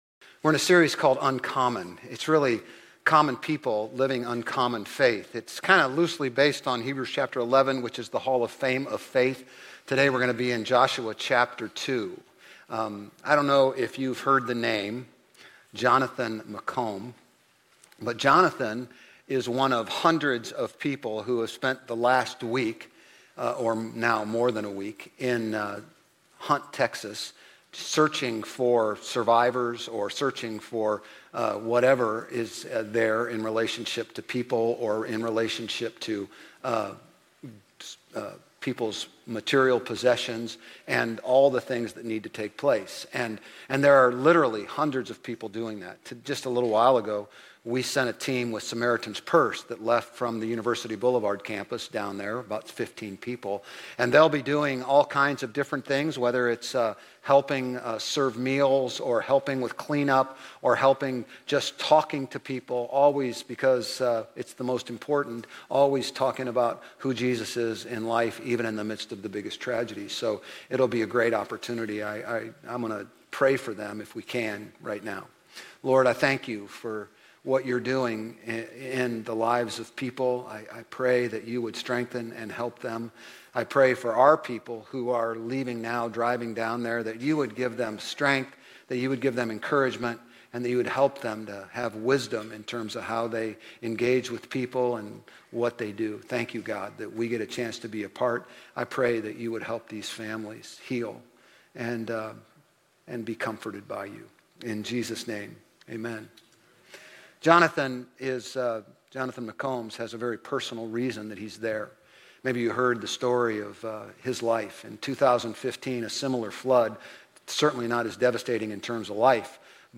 Grace Community Church Old Jacksonville Campus Sermons 7_13 Old Jacksonville Campus Jul 14 2025 | 00:33:13 1x 00:00 / 00:33:13 Subscribe Share RSS Feed Share Link Embed